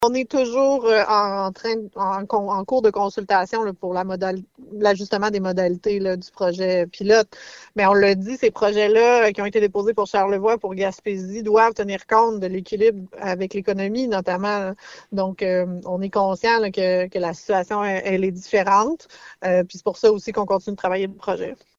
La ministre veut aussi se montrer flexible quant aux mesures qui seront mises en place pour protéger le cervidé en voie de disparition :